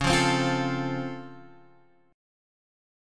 Db+M7 chord